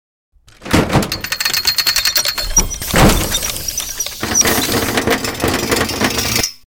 Привод, устройство № 3 — звуковой кино эффект